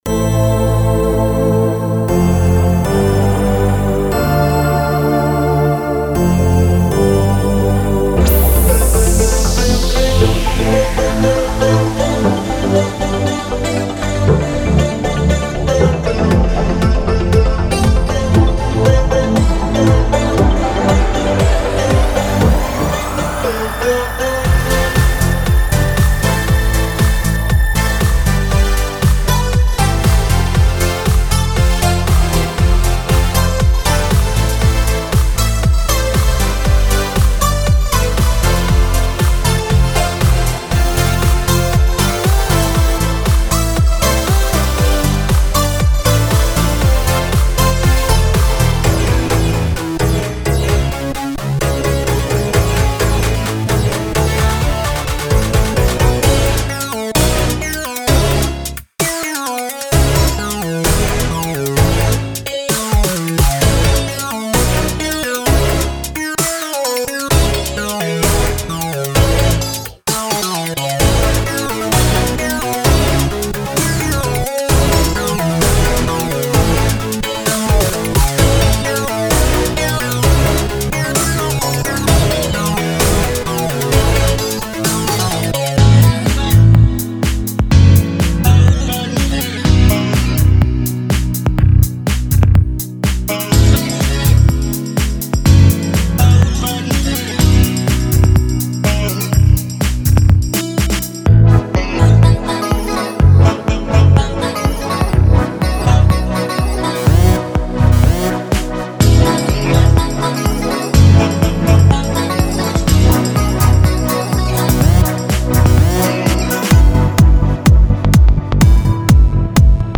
This set contains 111 presets for Electra and is suitable for contemporary music.
* Many patches are multi-layered or contain new multi-samples.
Dance, Pop, Commercial, Charts, Big Room, Electronic, Trap, HipHop, House